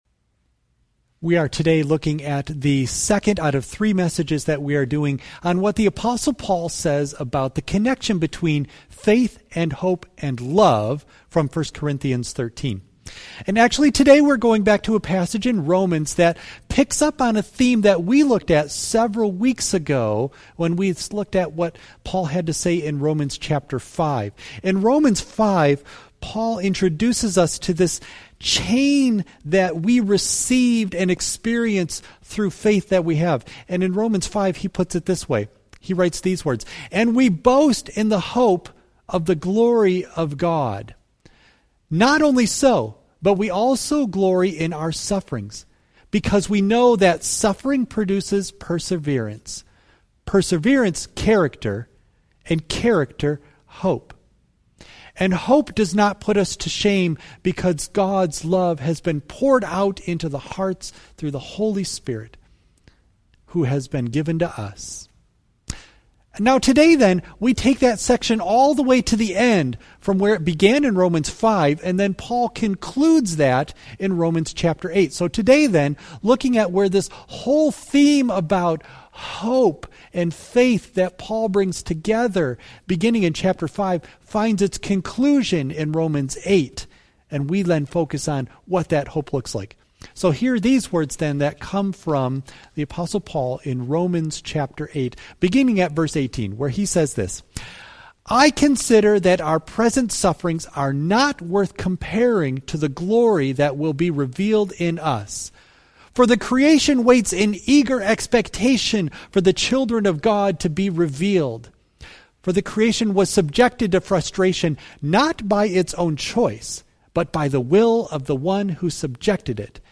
Preacher
Worship Service May 24 Audio only of message